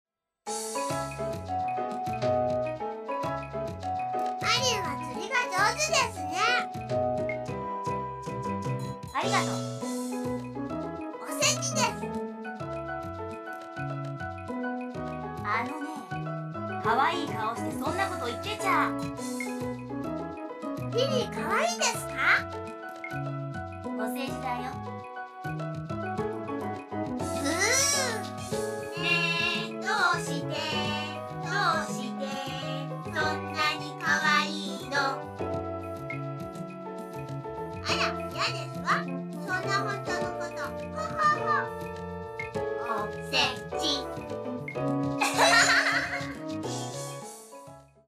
音量は意図的に小さめにしてあります。